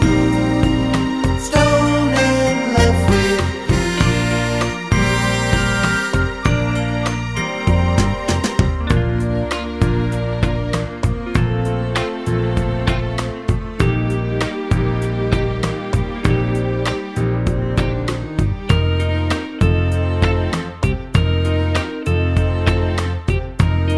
no Backing Vocals Soul / Motown 3:17 Buy £1.50